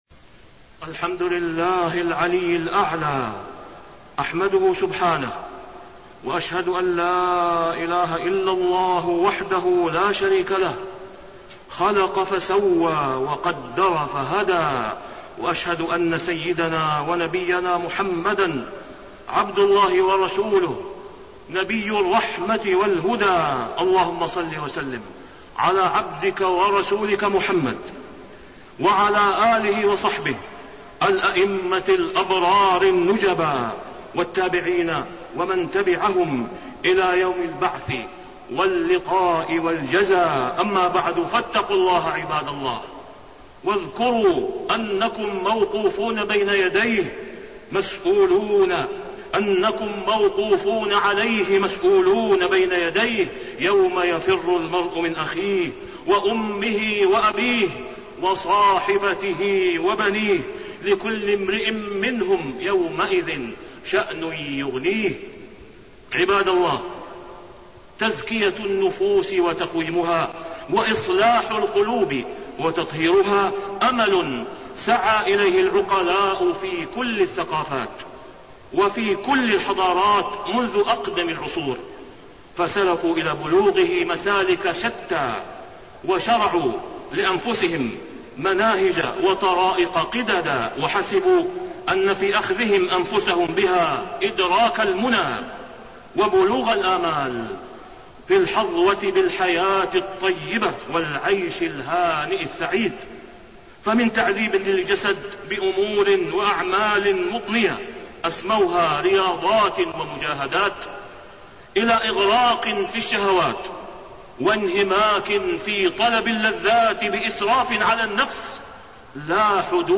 تاريخ النشر ١١ ربيع الأول ١٤٣٣ هـ المكان: المسجد الحرام الشيخ: فضيلة الشيخ د. أسامة بن عبدالله خياط فضيلة الشيخ د. أسامة بن عبدالله خياط تزكية النفوس وإصلاح القلوب